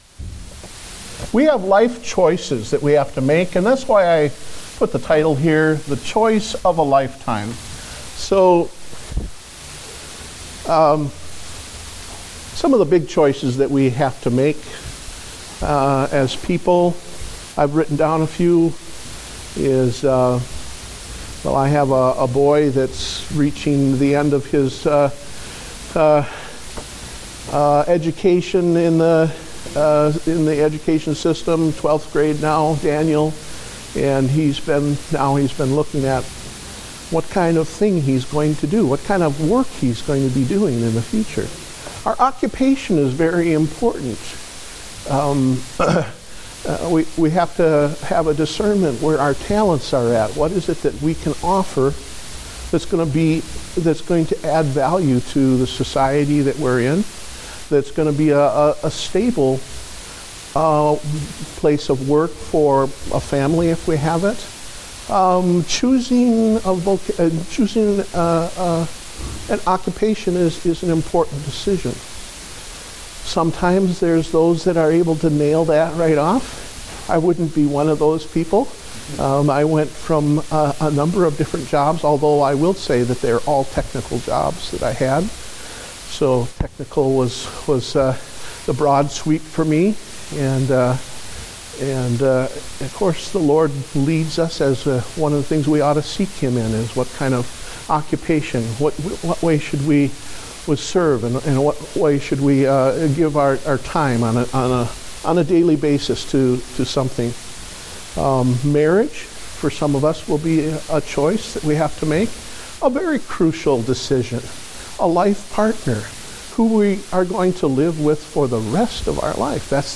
Date: September 20, 2015 (Adult Sunday School)